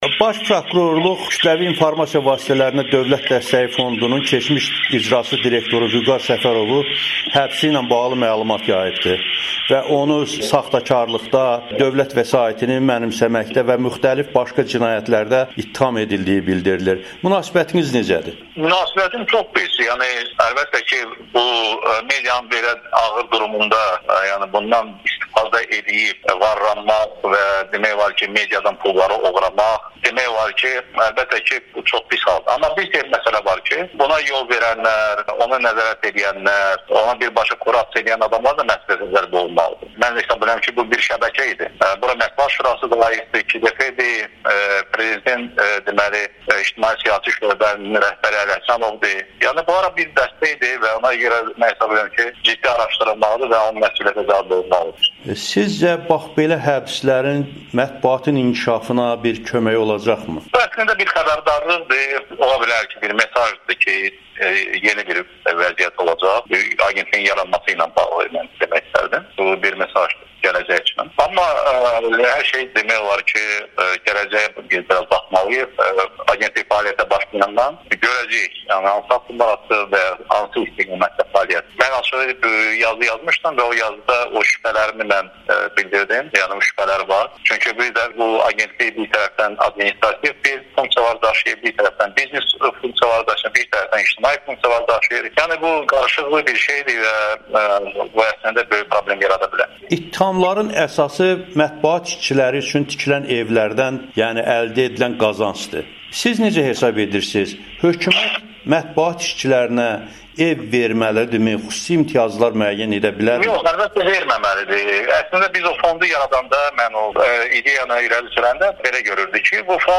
“Dövlət mediaya kommersiya strukturu kimi şərait yaratmalıdır. Belə olarsa media strukturaları özləri pul qazanıb ev də ala bilər, yaxşı əmək haqqı da verə bilər. İmtiyazlar, ev verilməsi əslində medianın ələ alınması deməkdir,” o Amerikanın Səsinə müsahibəsində qeyd edib.